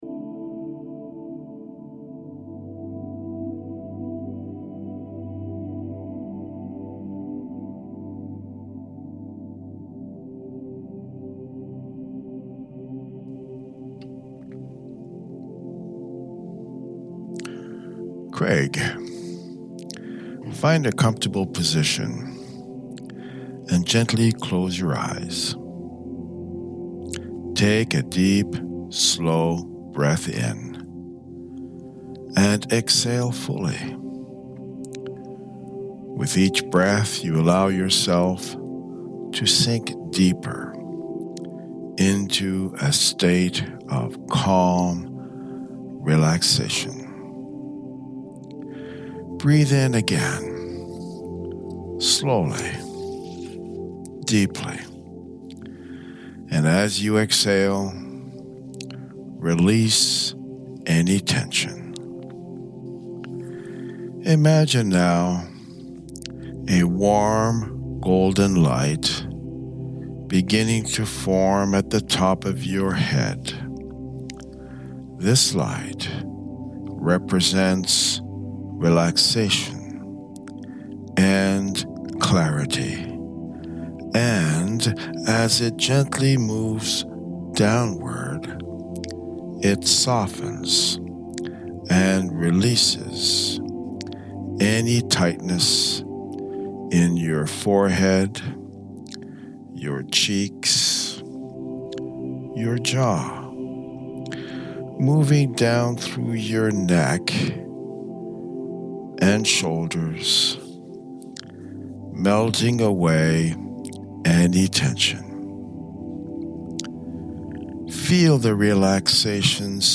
Stop Procrastination Instructions: This hypnosis is to be experienced in the morning.
Stop Procrastination - Alpha Music.mp3